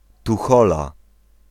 Pl-Tuchola.ogg